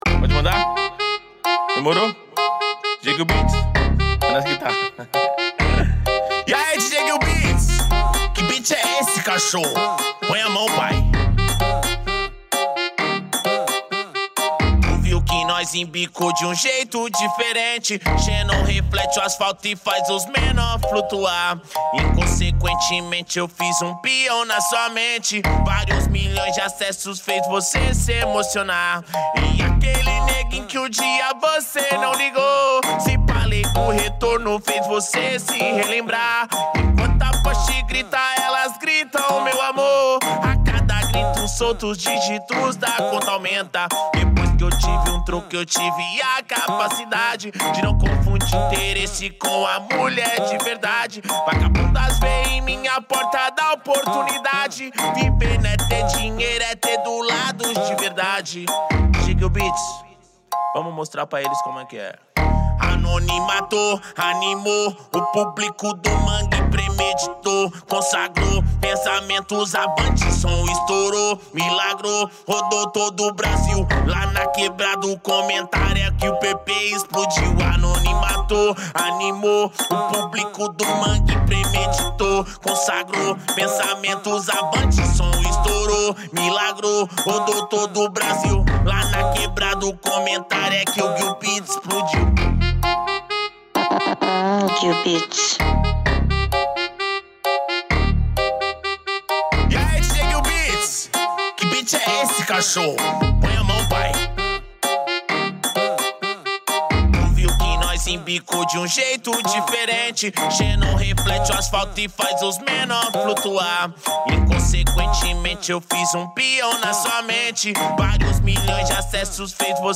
2024-04-15 01:03:23 Gênero: Funk Views